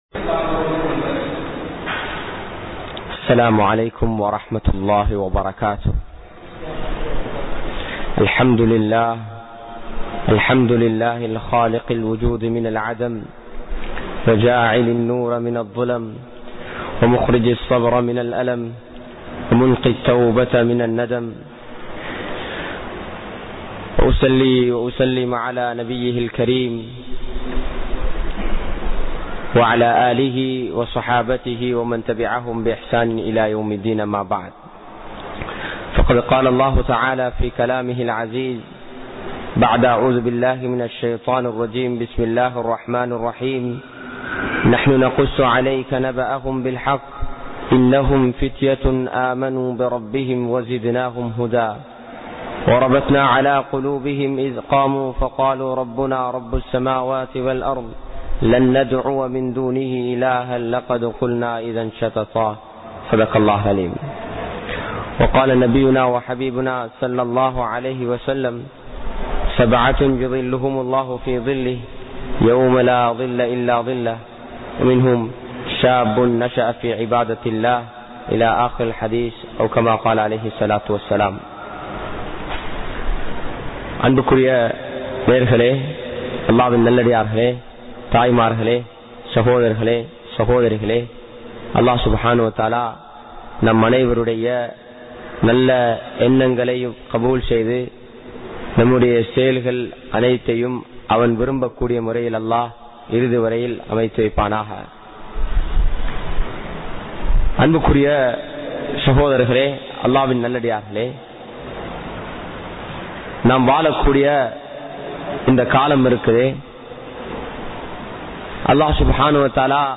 Vaalifarhalin kadamaihal(Duties of Youth) | Audio Bayans | All Ceylon Muslim Youth Community | Addalaichenai